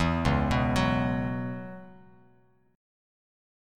C#m11 chord